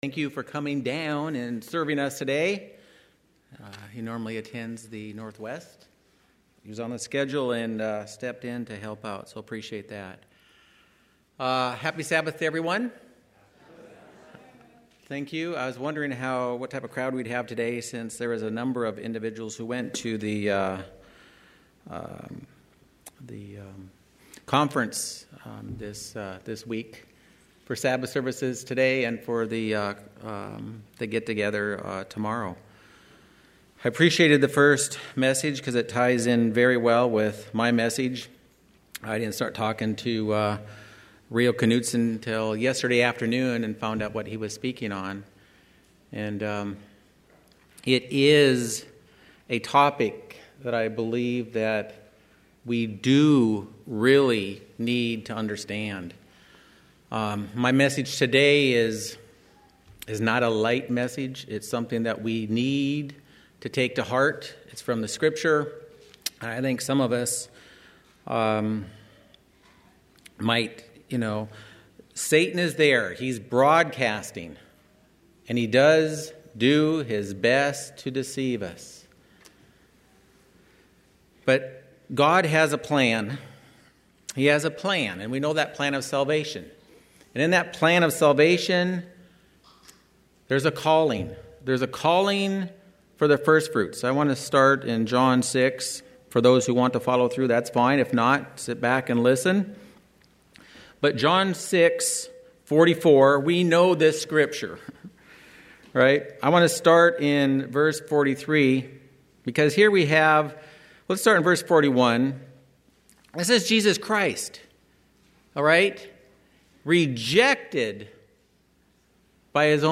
Sermons
Given in Phoenix East, AZ